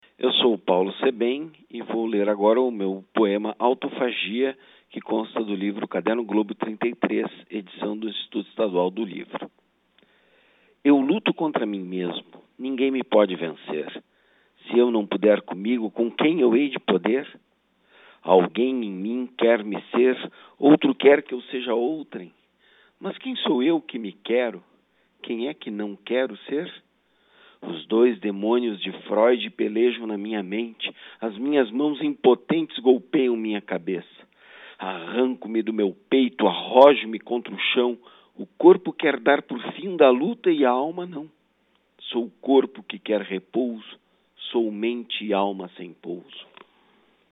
lê trecho do poema Autofagia, do livro Caderno Globo 33, publicado pelo IEL.